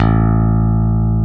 Index of /90_sSampleCDs/Roland L-CD701/BS _E.Bass 2/BS _Rock Bass
BS  ROCKBSA2.wav